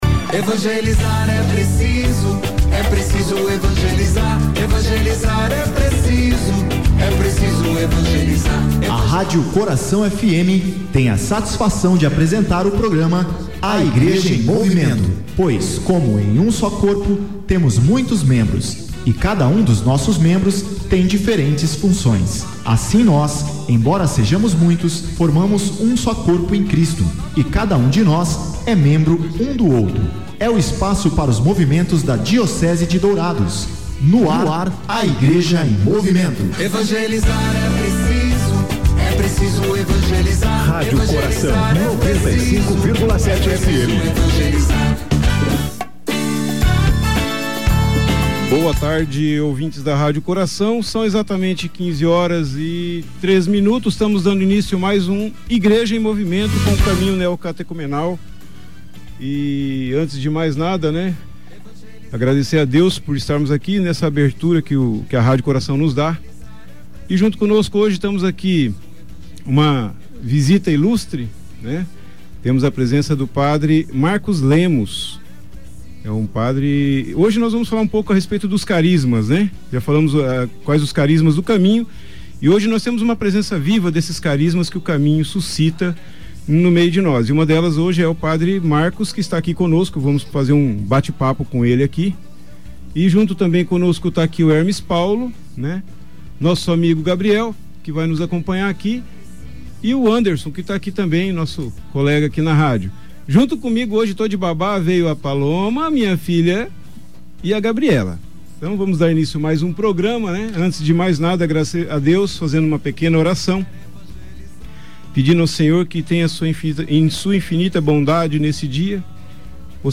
O programa 'A Igreja em Movimento' vai ao ar todos os sábados das 15 às 16 horas.